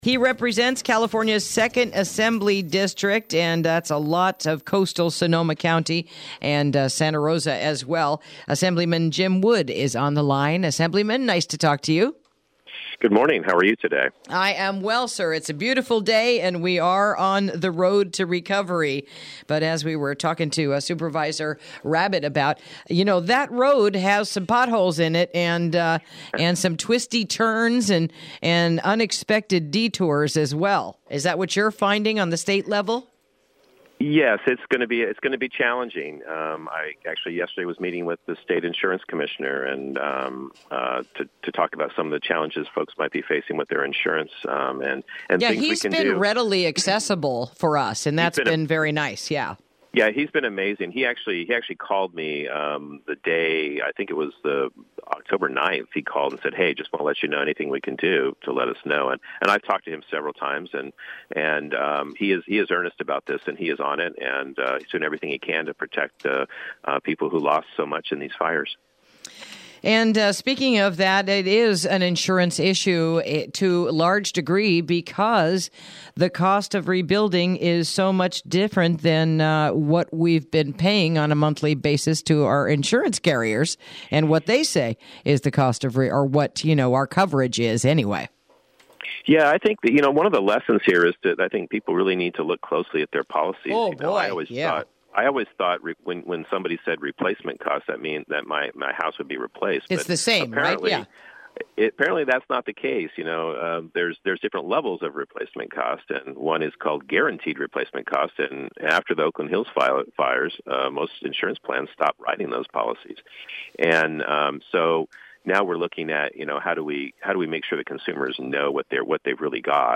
Interview: Assemblyman Jim Wood | KSRO 103.5FM 96.9FM & 1350AM
Assemblyman Jim Wood, joins us to talk about healthcare funding lost on CHP, universal healthcare meetings, and PG&E bill preventing wildfire costs onto customers.